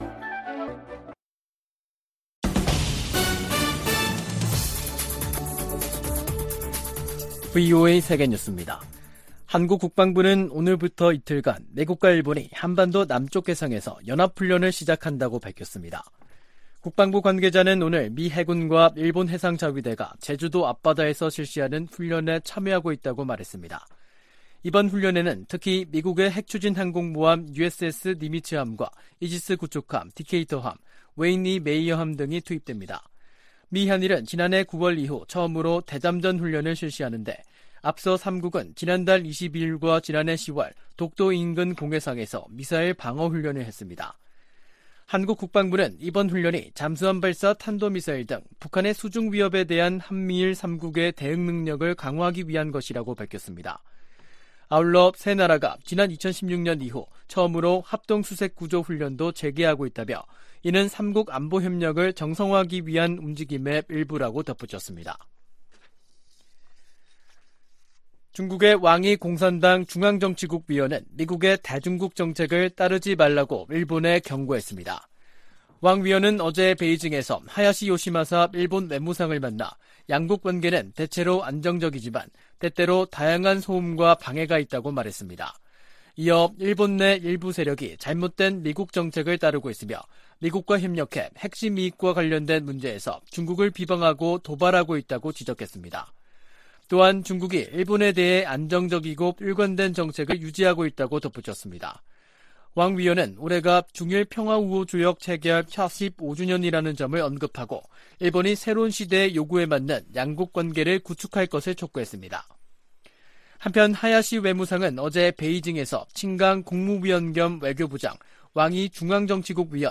VOA 한국어 간판 뉴스 프로그램 '뉴스 투데이', 2023년 4월 3일 3부 방송입니다. 미 상원이 대통령 무력사용권 공식 폐지 법안을 가결했습니다.